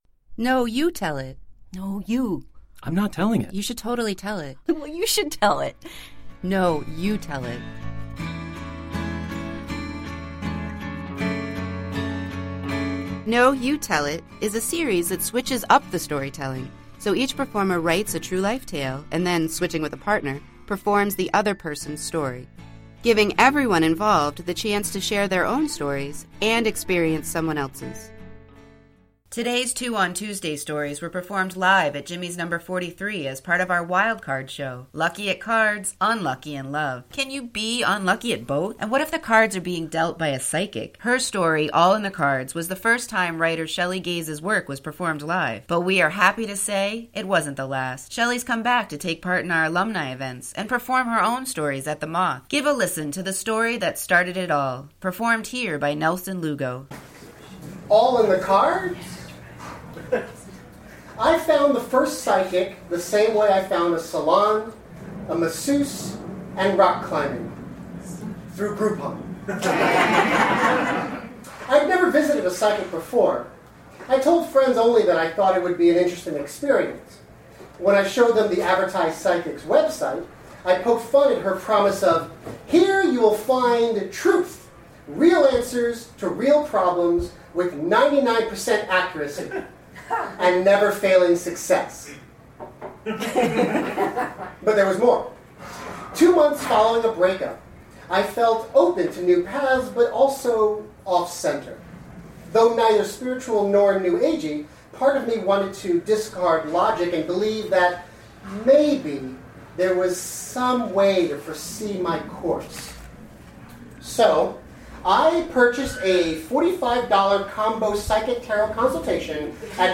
Today’s “Two on Tuesday” stories were performed live at Jimmy’s No. 43 as part of our WILD CARD show. Lucky at cards, unlucky in love.